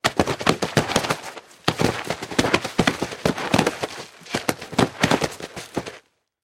Звуки кувырка
Звук падения тела с горы и его перекатывания по камням